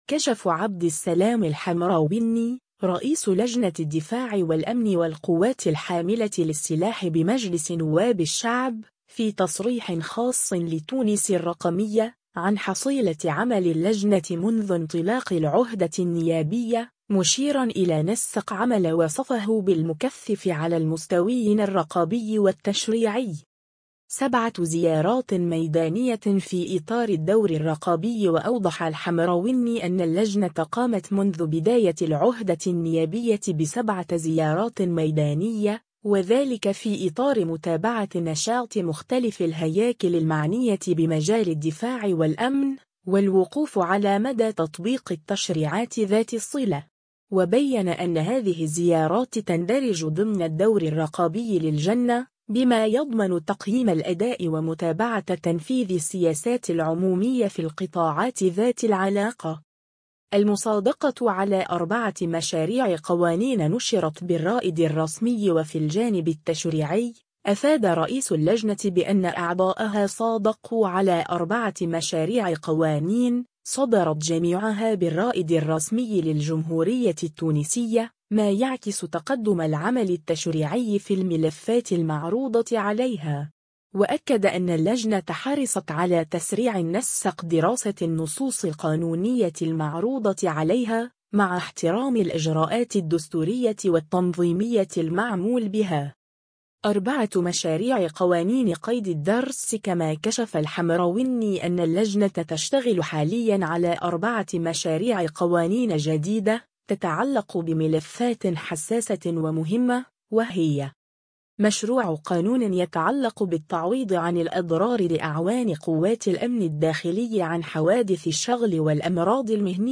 كشف عبد السلام الحمروني، رئيس لجنة الدفاع والأمن والقوات الحاملة للسلاح بمجلس نواب الشعب، في تصريح خاص لـ”تونس الرقمية”، عن حصيلة عمل اللجنة منذ انطلاق العهدة النيابية، مشيرًا إلى نسق عمل وصفه بـ”المكثف” على المستويين الرقابي والتشريعي.